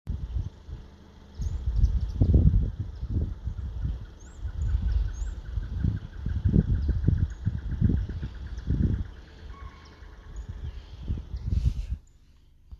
Axis axis
Família: Cervidae
Nome em Inglês: Chital
Localidade ou área protegida: A Parque Nacional El Palmar (-31,852171, -58,323222)
Certeza: Gravado Vocal